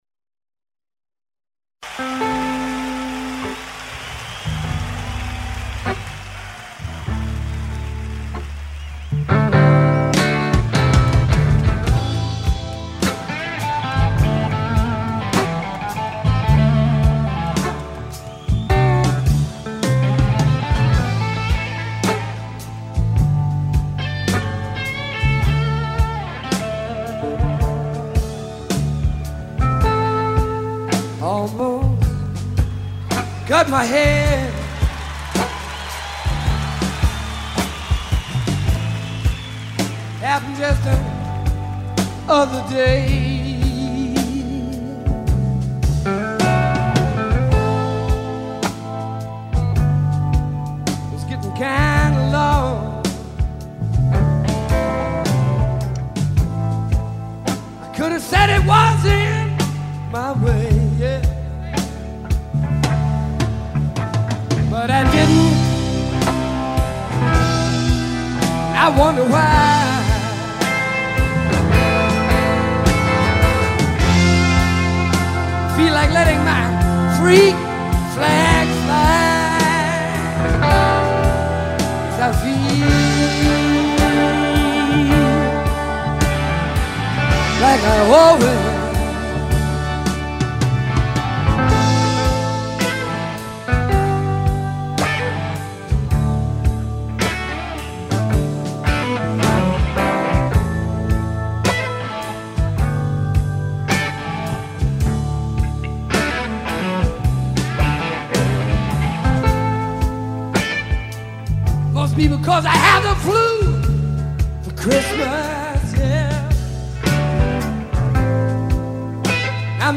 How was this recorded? stadium tour